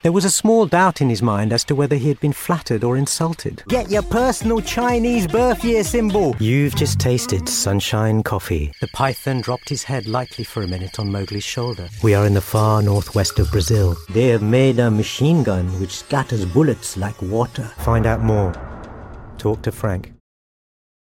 Commercial Showreel - Latin American Spanish